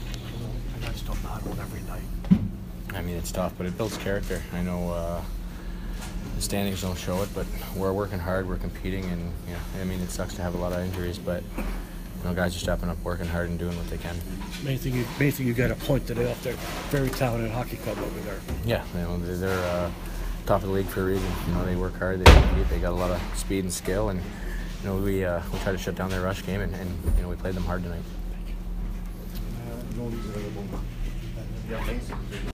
Andrew Shaw post-game 3/10